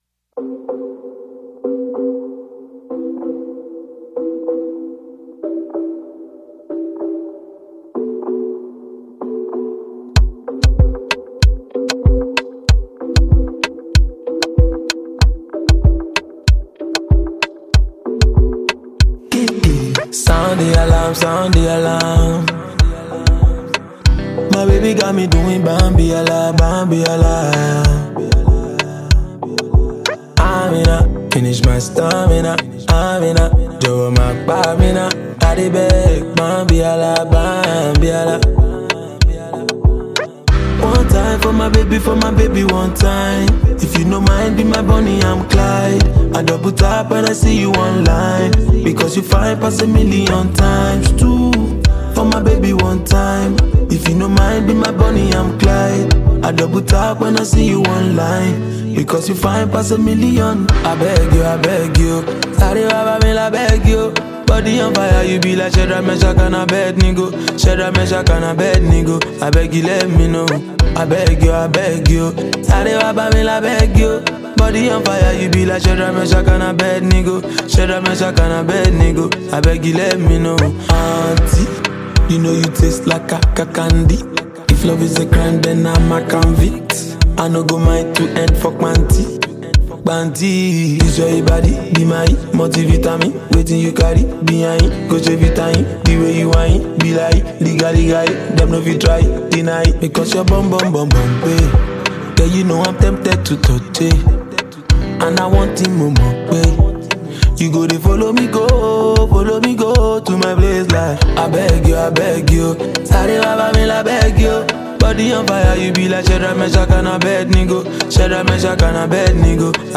Nigerian singer and songwriter
ear-catchy Afro-Pop and Amapiano-infused record
calm and melodious beats